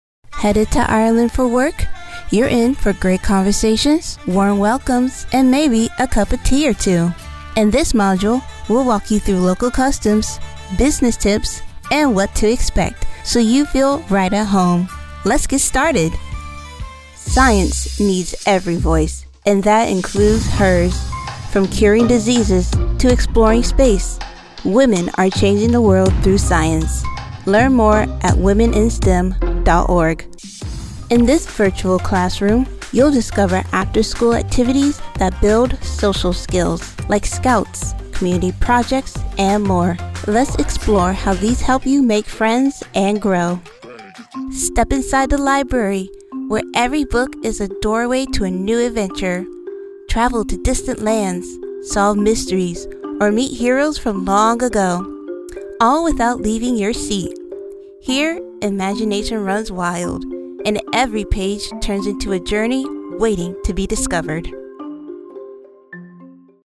Narration Demo
English - Southern U.S. English